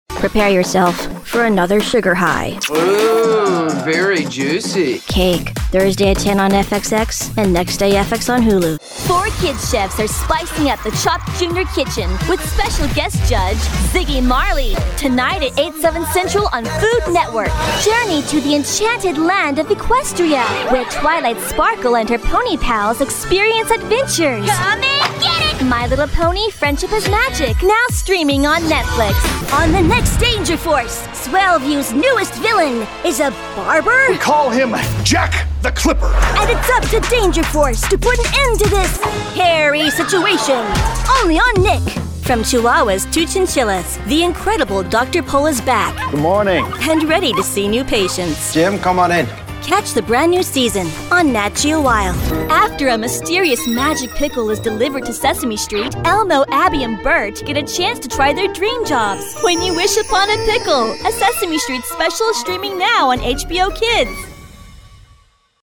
Young Adult, Adult
Has Own Studio
standard us | natural
tv promos